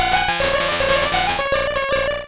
Cri de Lippoutou dans Pokémon Rouge et Bleu.